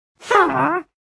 Dźwięk bezczynności wiedźmy nr.2
Witch2.wav